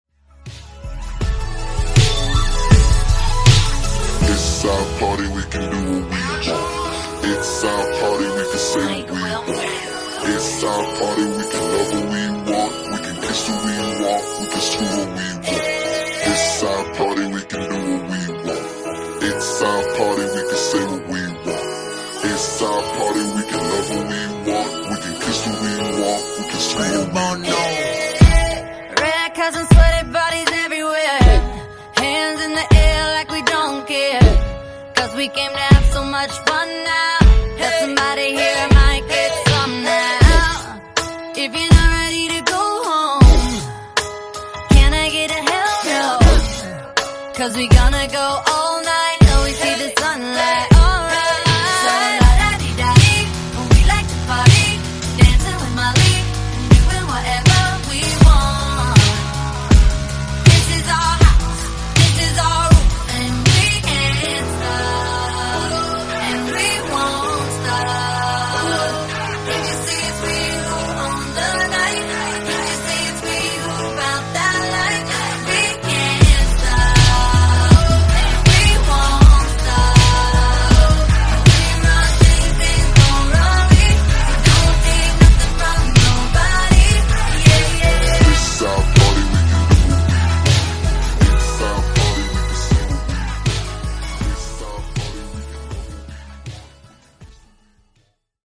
Genre: RE-DRUM
Clean BPM: 80 Time